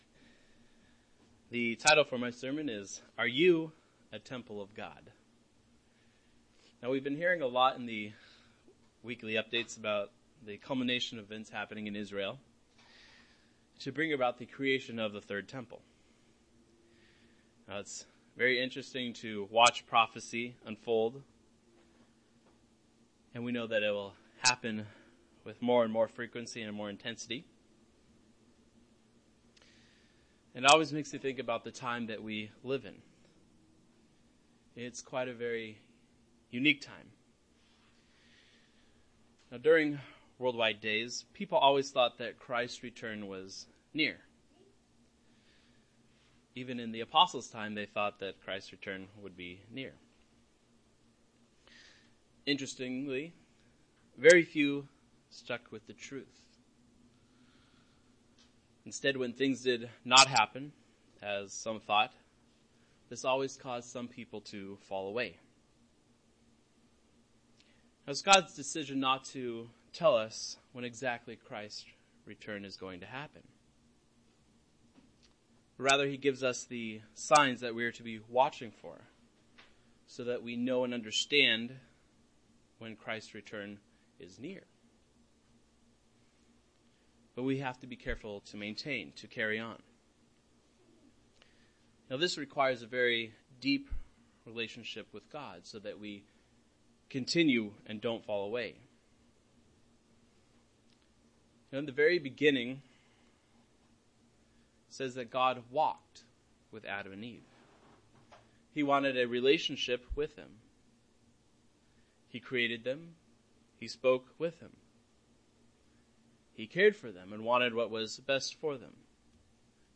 Sermons – Page 81 – Church of the Eternal God